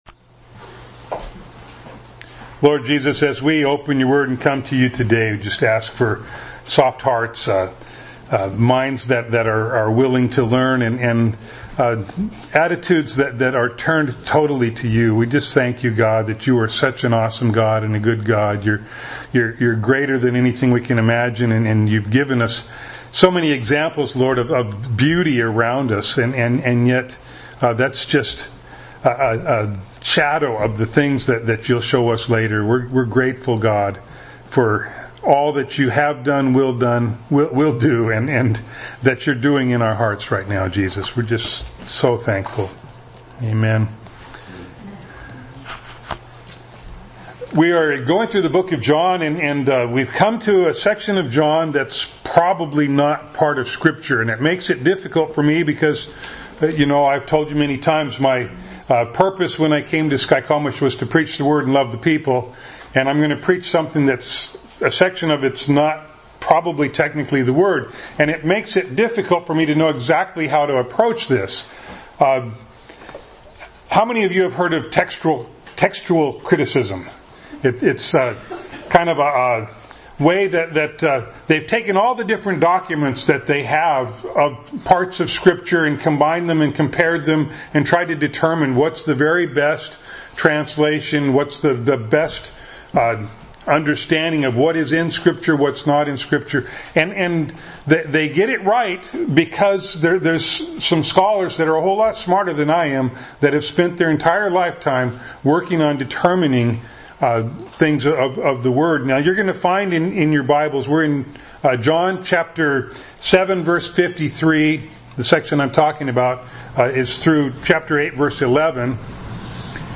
John 7:53-8:30 Service Type: Sunday Morning Bible Text